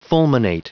Prononciation du mot fulminate en anglais (fichier audio)